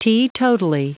Prononciation du mot : teetotally
teetotally.wav